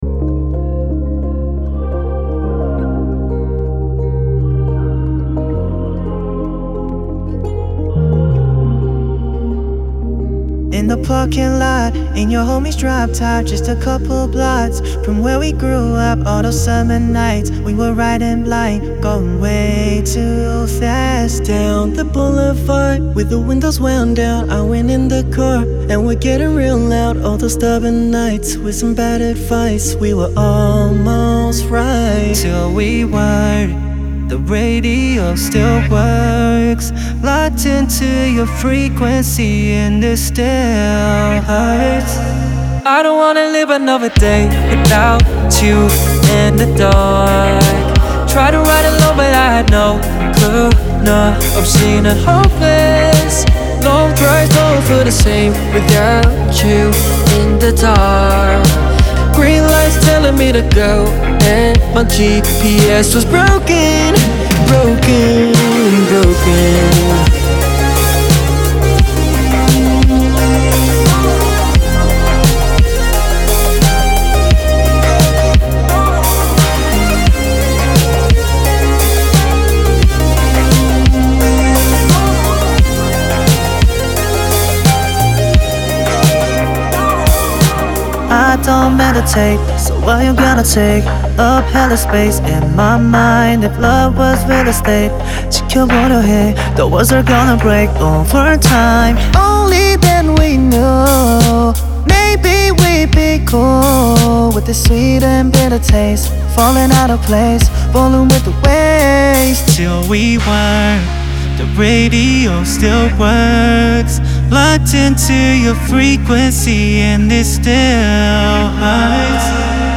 K-pop